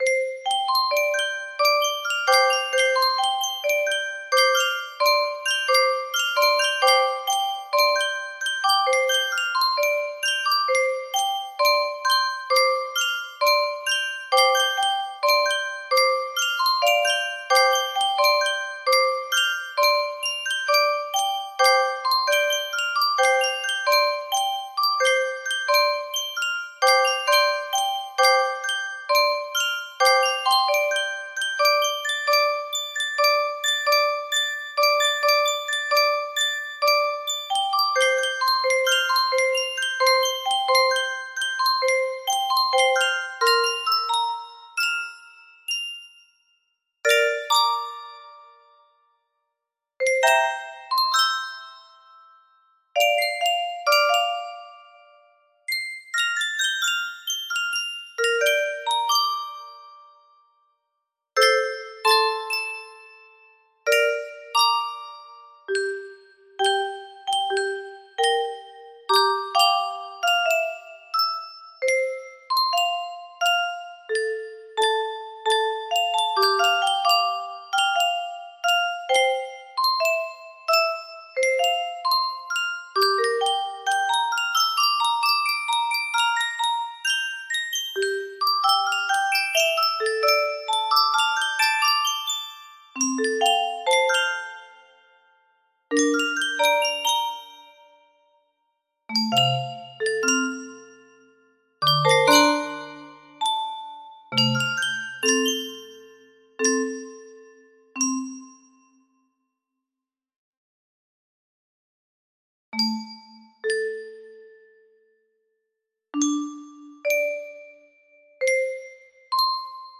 🔫 Nerf Gun 🔫 Versus World🔫 music box melody
Full range 60